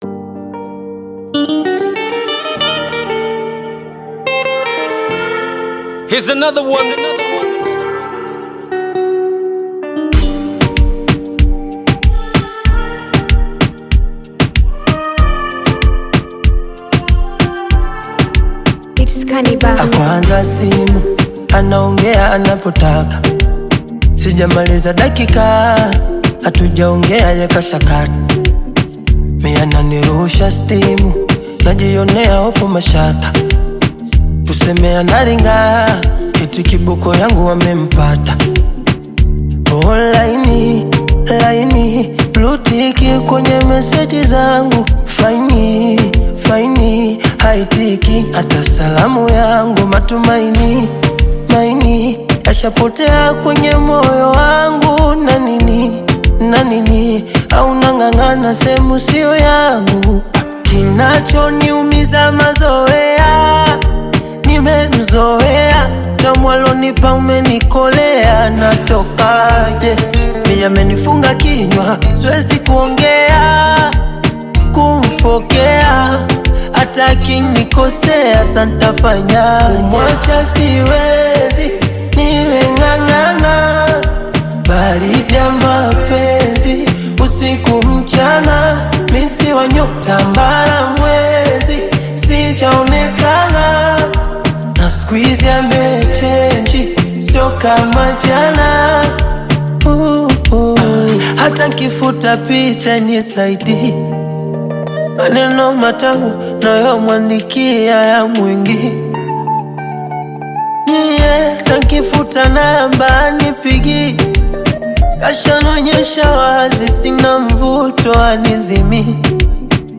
Bongo Flava music track
Tanzanian Bongo Flava artist, singer and songwriter